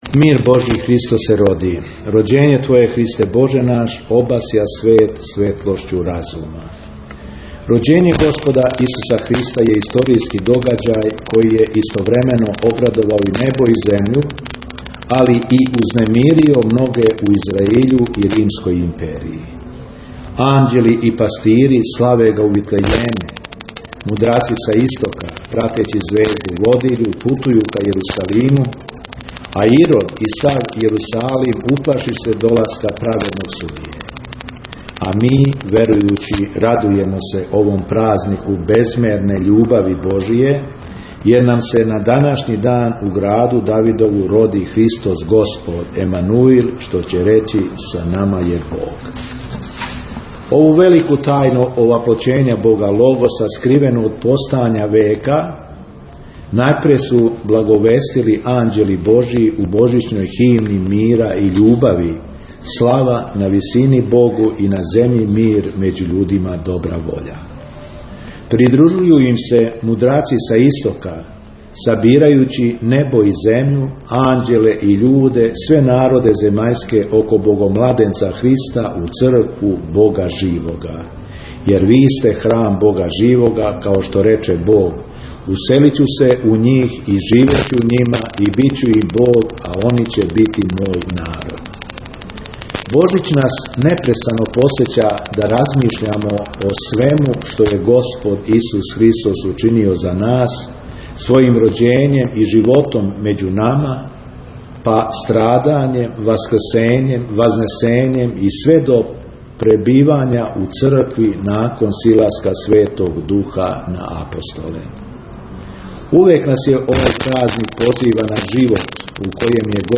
Послушајте посланицу Његовог Преосвештенства Епископа Шумадијског Господина Јована о Божићу 2020. год.